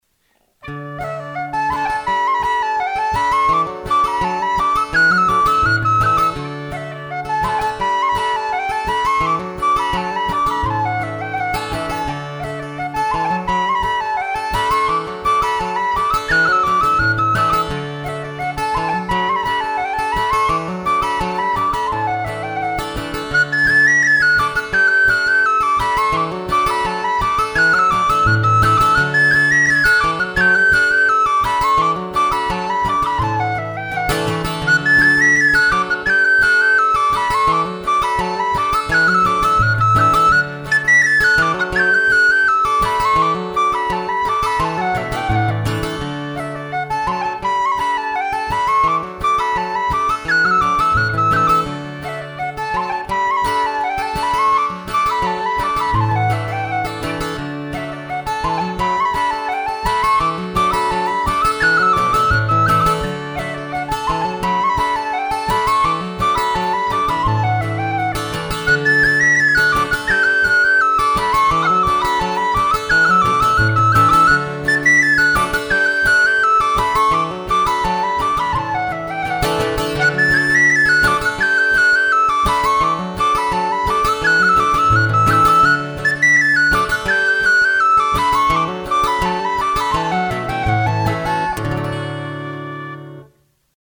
Hand-Crafted Aluminium Whistles
I offer a full range of quality custom built hand-crafted aluminium whistles, in any key from high E to bass G. My whistles have a rich powerful tone, mellow with a touch of complexity.